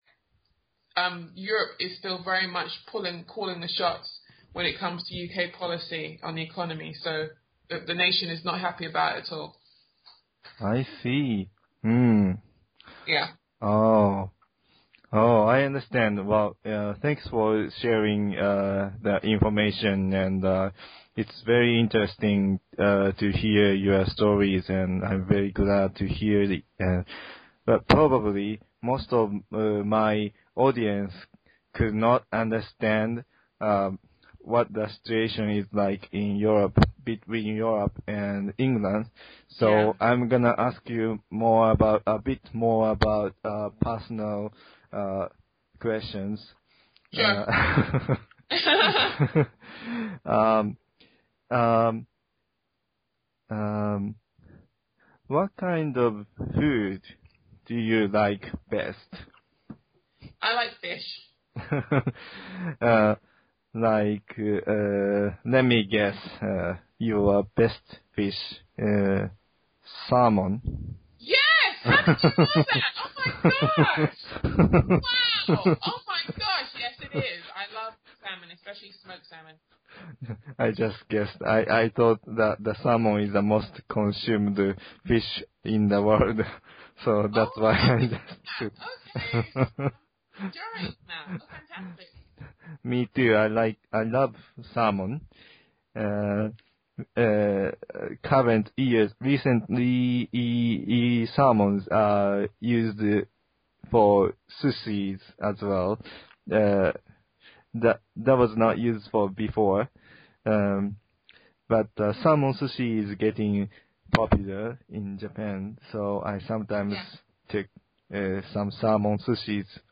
relaxed Chat